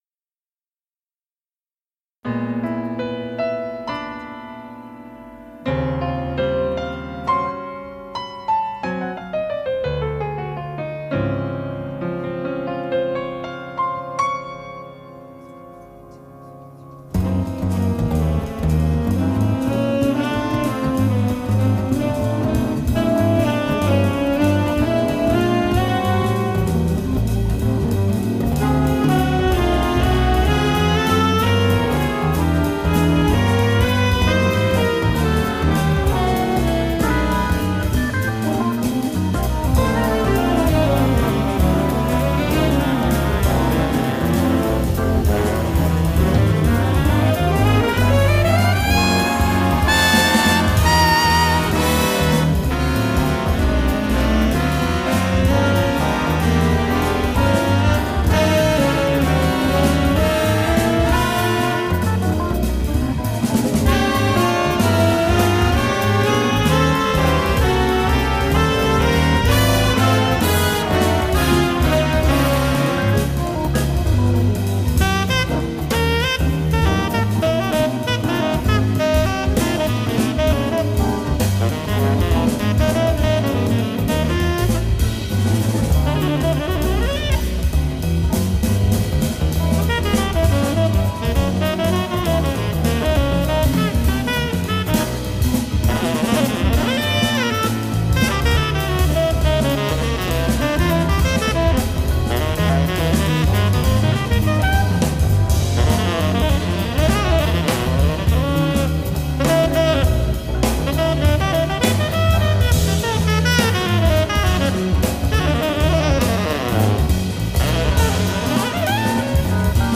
Recorded at the German Jazz Festival - October 24, 1999.